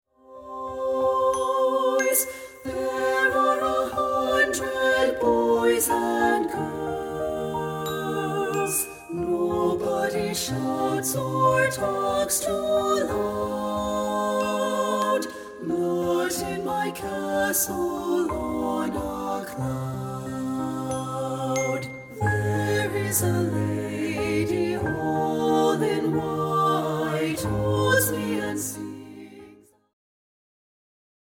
• Full Mix Track